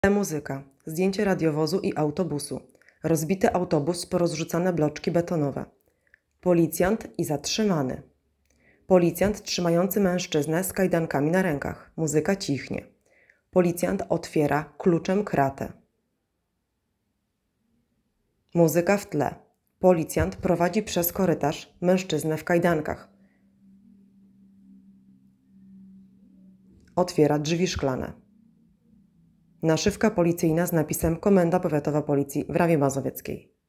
Nagranie audio audiodeskrycja filmu.m4a